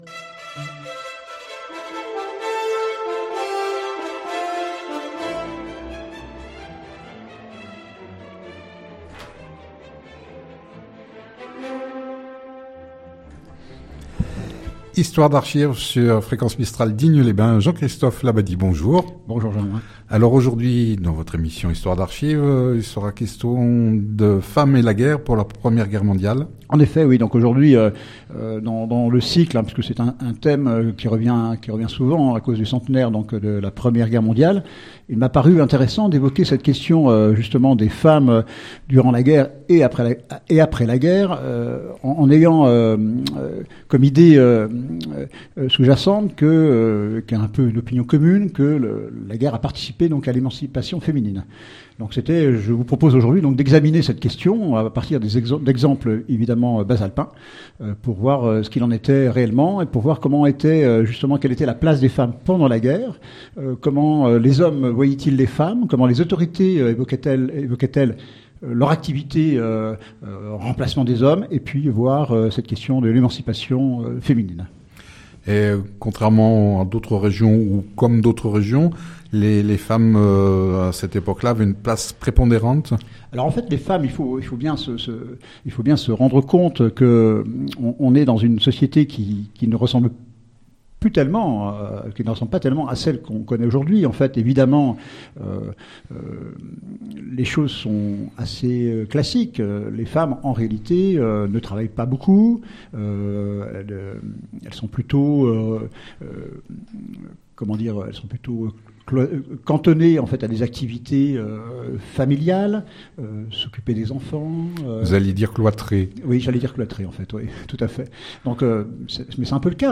Cette émission sur fréquence mistral à Digne, a lieu tous les 3èmes jeudi du mois en direct de 9h10 à 10h